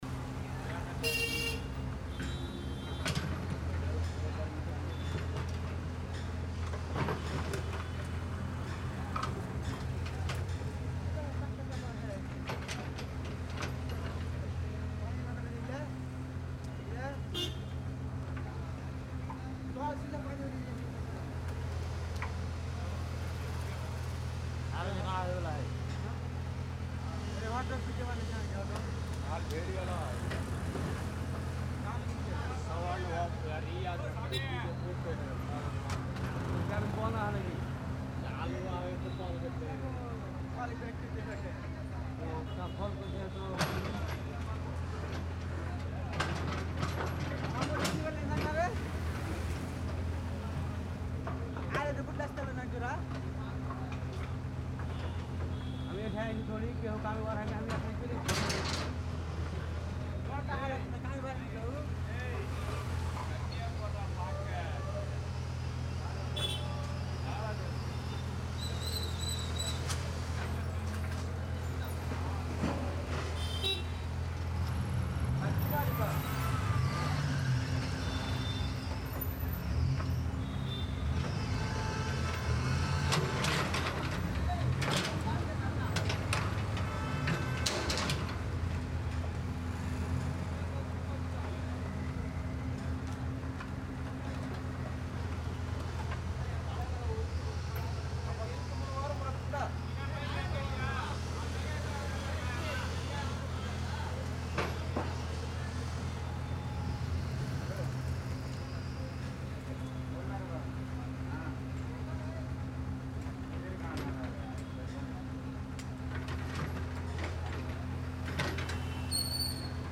Slum Backyard Ambience captures the raw, everyday soundscape of a densely populated residential area. This ambience blends soft human activity, distant conversations, utensils clanking, light footsteps, children playing, birds, mild construction taps, and natural environmental texture. The sound reflects the authentic rhythm of community life—busy yet warm, chaotic yet familiar.
Cleanly mixed for background use without overpowering main dialogue.
Urban / Human Life
Slum / Backyard Activity
Natural & Busy
Outdoor Environmental Field
Thirtyseven-slumbackyard-labour-talking.mp3